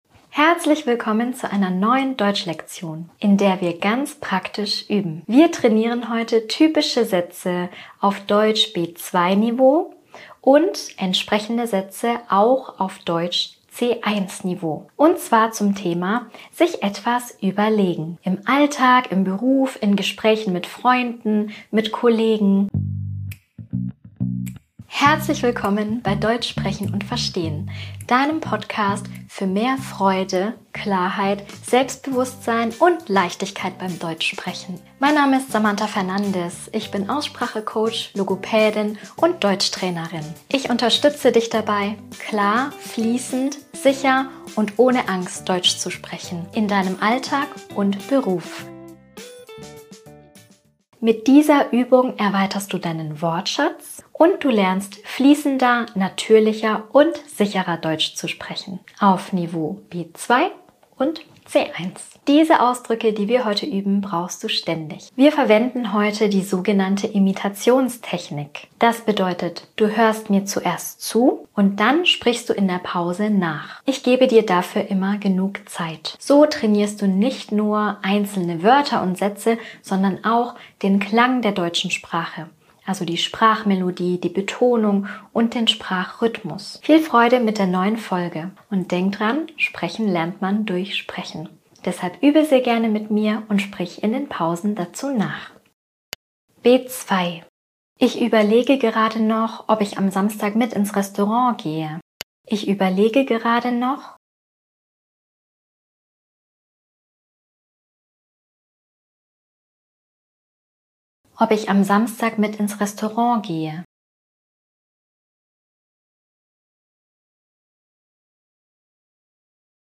In dieser Podcastfolge übst du, wie du „Ich überlege noch …“ auf Deutsch B2- und C1-Niveau natürlich, sicher und flüssig in Sätzen für den Alltag und Beruf ausdrückst. Du hörst typische Formulierungen und einen Dialog aus dem echten Leben.
Hör zuerst zu sprich in den Pausen laut nach und entwickle ein sicheres Sprachgefühl für spontanes Deutsch.